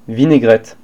프랑스어 발음